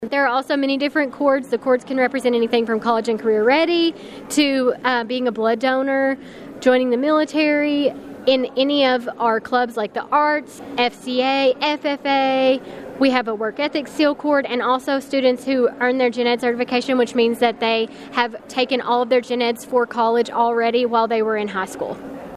The senior class of Caldwell County High School received their graduation cords and stoles Monday morning in a special presentation in the high school Fine Arts Building.